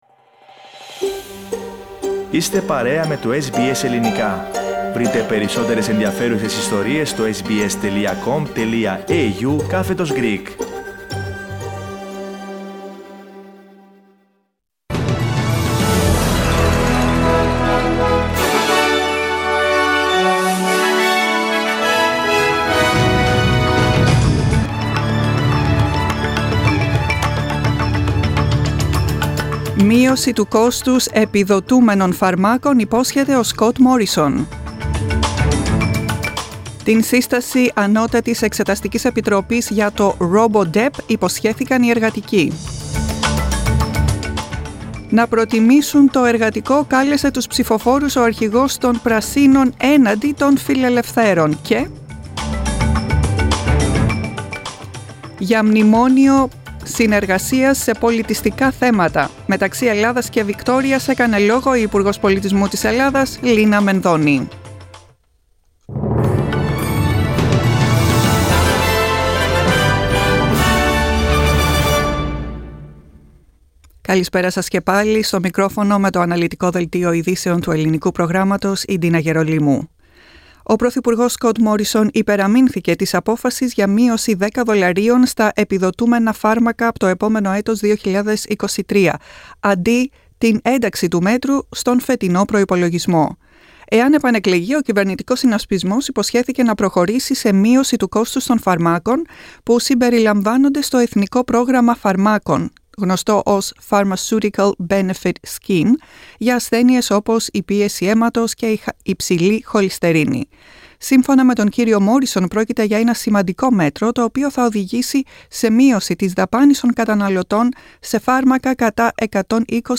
Listen to the main bulletin from the Greek Program on Saturday 30 April 2022.